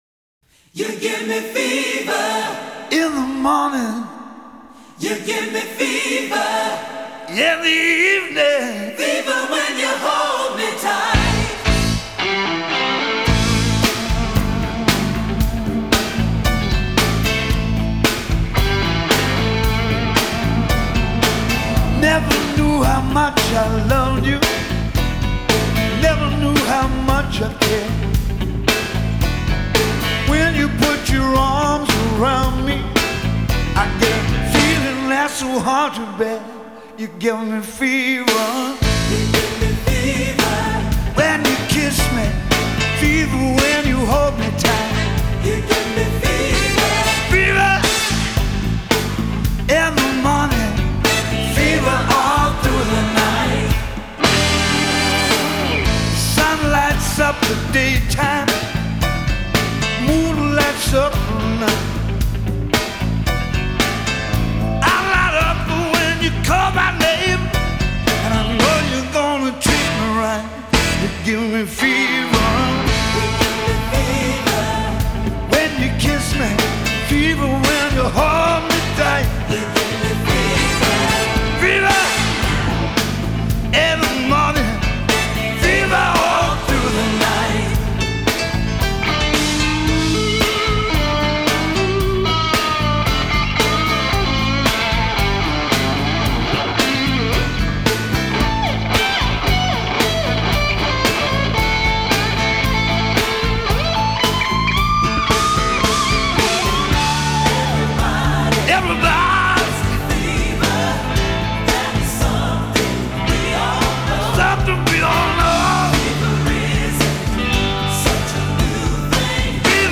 характерным хриплым вокалом